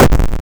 boom3.wav